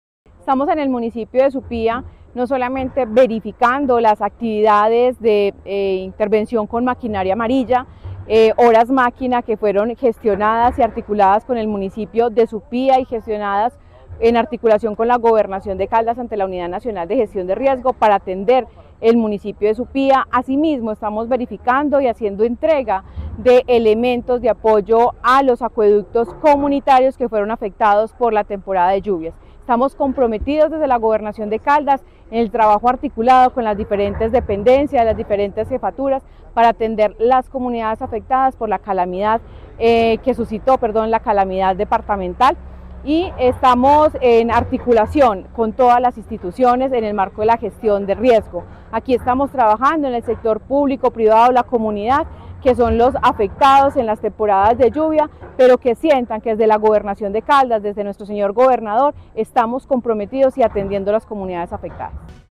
Paola Andrea Loaiza Cruz, secretaria de Medio Ambiente de Caldas.